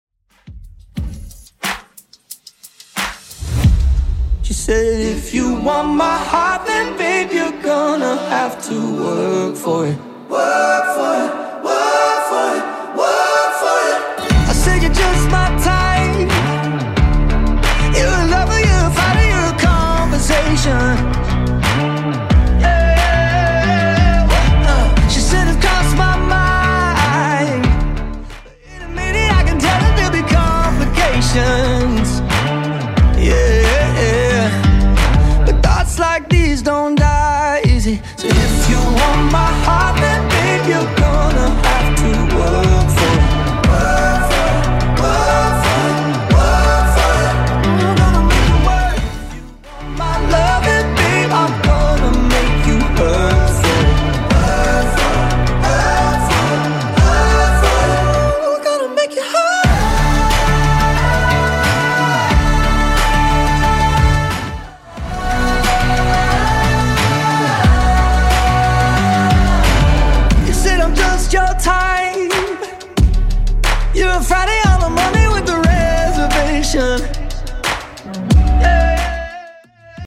Genre: 70's
BPM: 114